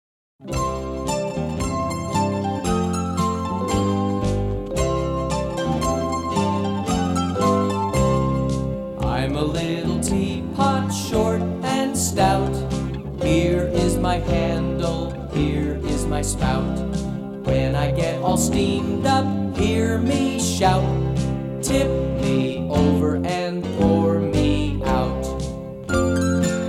Traditional Children's Action Song